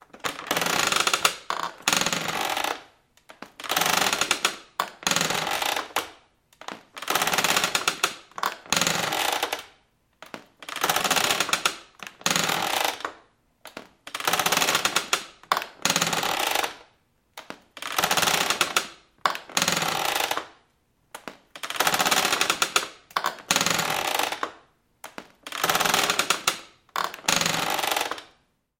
Деревянное кресло качалка поскрипывает